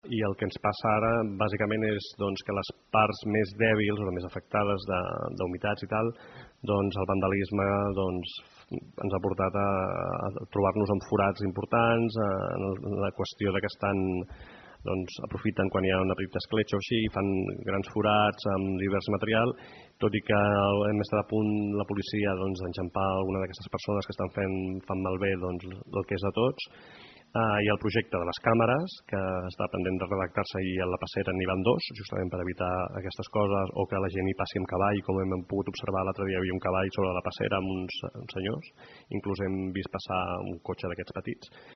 Anteriorment s’han realitzat tasques de manteniment com ara la substitució de plafons malmesos per la humitat, però per la regidoria d’urbanisme alerta que la majoria de destrosses són provocades per actituds vandàliques. Són declaracions de Josep Rueda, regidor d’urbanisme.